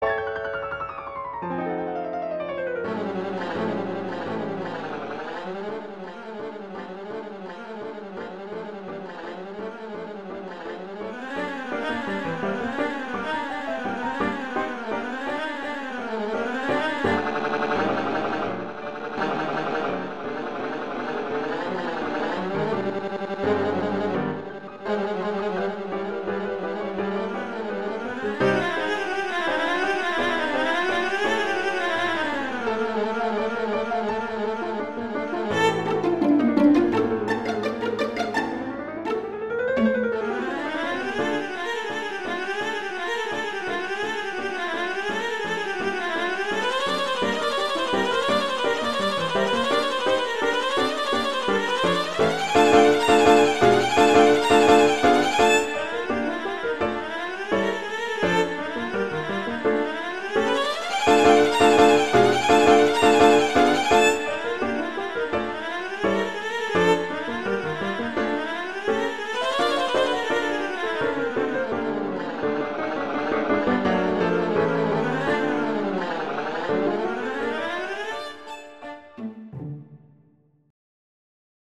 classical
D minor
♩=169 BPM (real metronome 168 BPM)